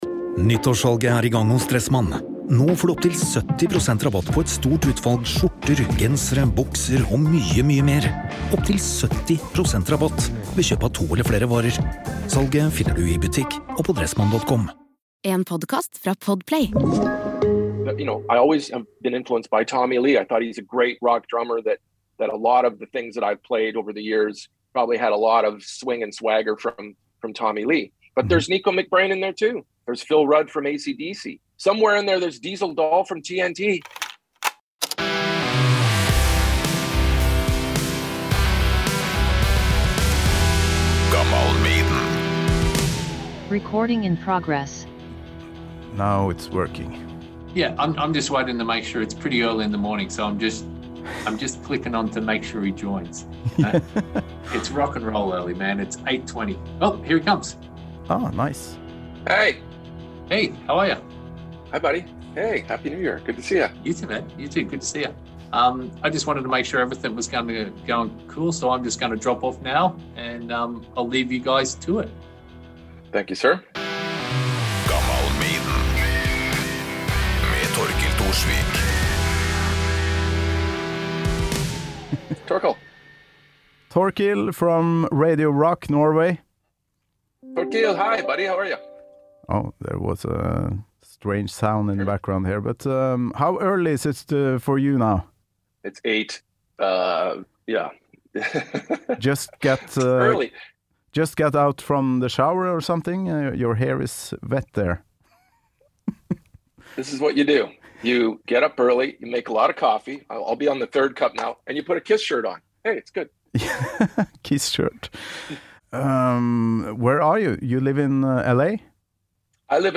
Han har spilt med dem "alle": Alice Cooper, Gene Simmons, The Guess Who, Vince Neil, Bruce Kulick, Jake E. Lee etc. Her snakker vi om oppveksten i Canada, konsertene, bandene og coverversjonen av Maiden-låta The Evil That Men Do som Fitz gjorde sammen med giga-Maiden-fan Chris Jericho. Faktisk skal vi også innom Tim Wendelboe-kaffe på Grünerløkka, TNT, Ronni Le Tekrø og Diesel Dahl i denne over gjennomsnittet koselige praten på direktelinje fra Las Vegas.